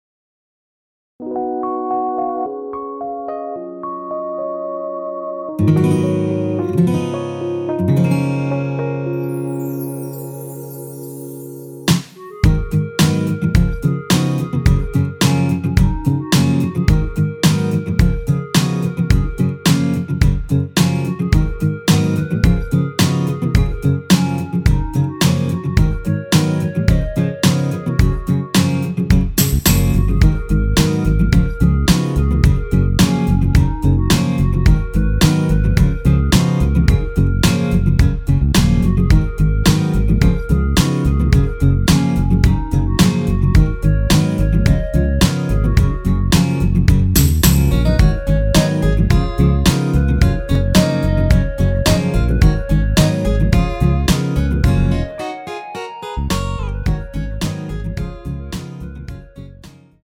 원키에서(-3)내린 멜로디 포함된 MR입니다.(미리듣기 확인)
Gb
앞부분30초, 뒷부분30초씩 편집해서 올려 드리고 있습니다.